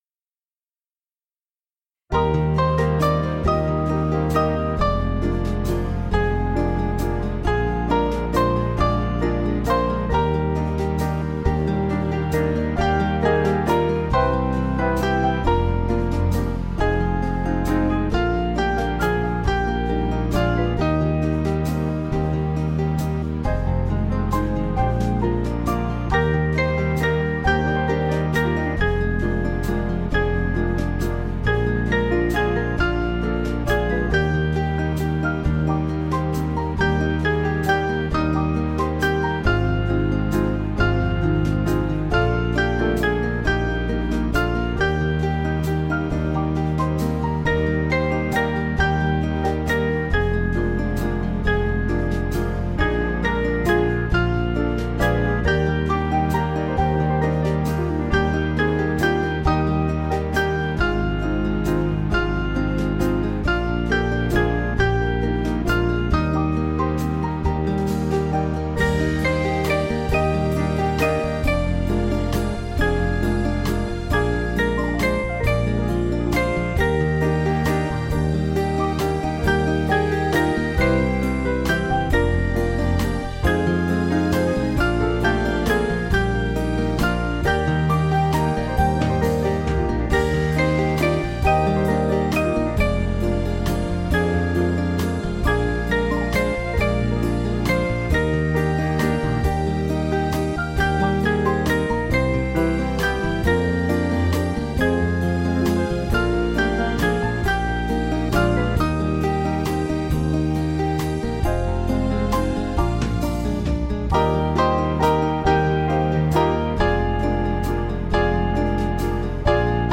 Small Band
(CM)   3/Eb 493.2kb